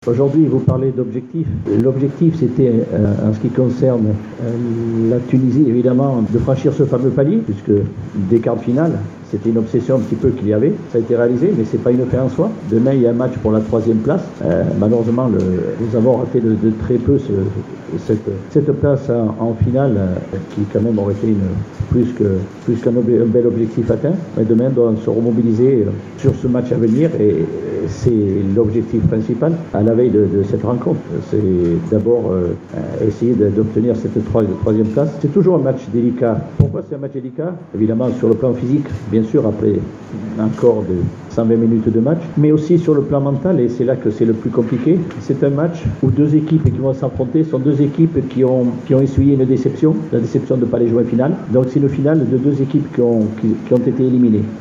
عقد مدرب المنتخب الوطني التونسي آلان جيراس ندوة صحفية اليوم الثلاثاء 16 جويلية 2019 صحبة اللاعب محمد دراغر للحديث حول المقابلة الترتيبية أمام المنتخب النيجيري المبرمجة يوم الإربعاء بداية من الساعة الثامنة ليلا بملعب السلام بالقاهرة.